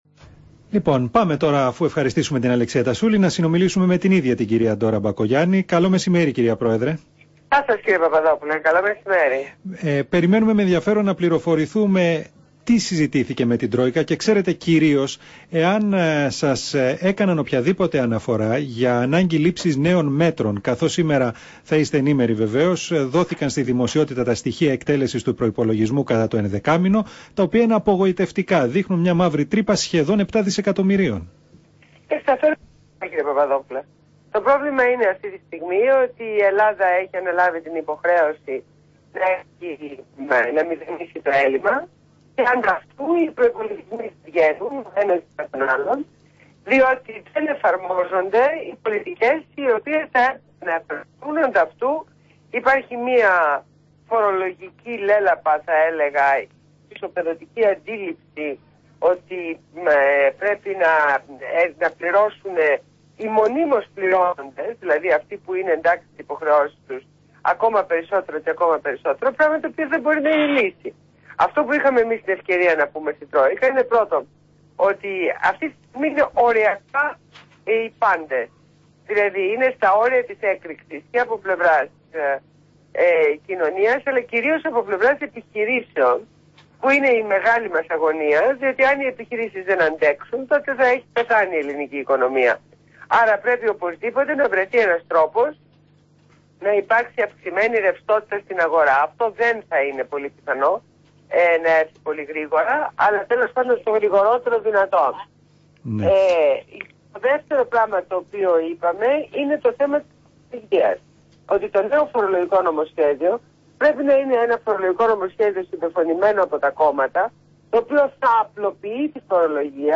Συνέντευξη Ντόρας Μπακογιάννη στο ραδιόφωνο Real fm 97.8 | Πρόεδρος